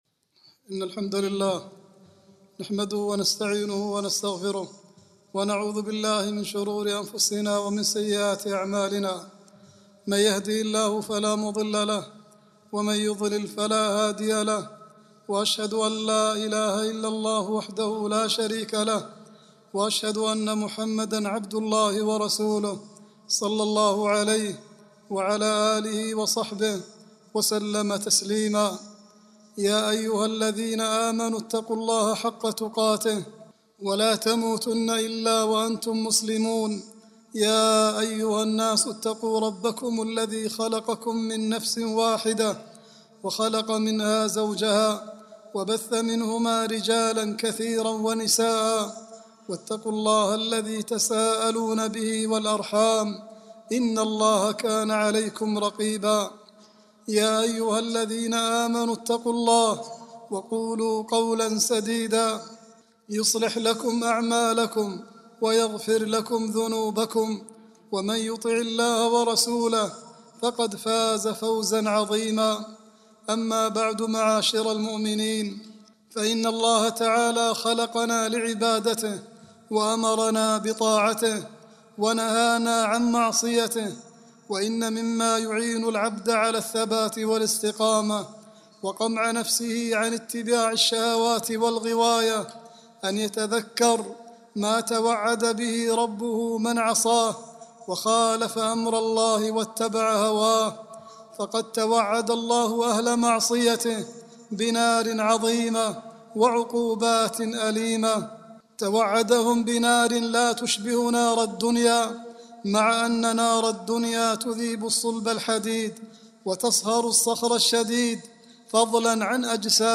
العنوان : صفة النار نعوذ بالله منها خطبة
khutbah-23-5-39.mp3